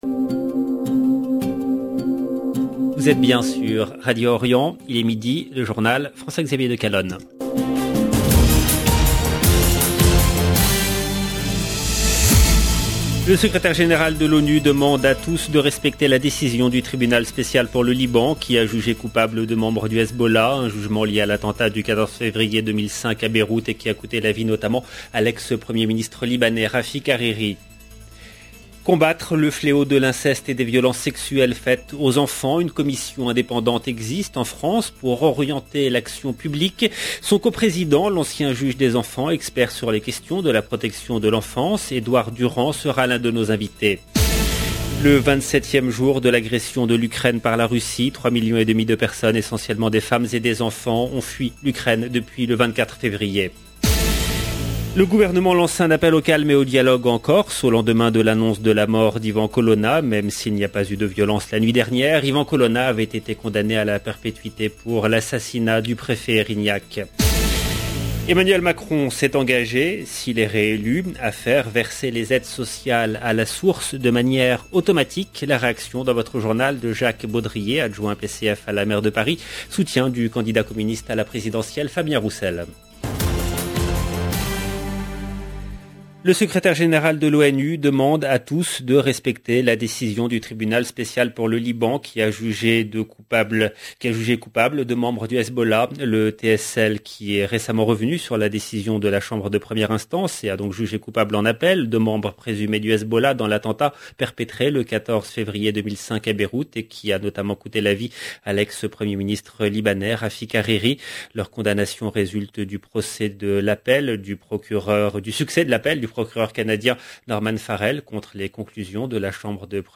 La réaction dans votre journal de Jacques Baudrier, adjoint PCF à la maire de Paris, soutien du candidat communiste à la présidentielle Fabien Roussel. 0:00 17 min 36 sec